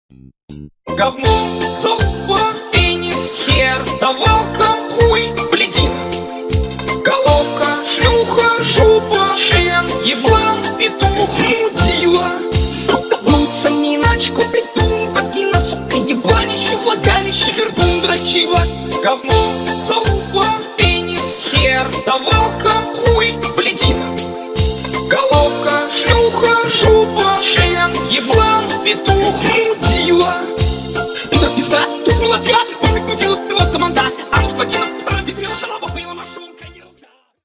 Внимание Ненормативная лексика!
весёлые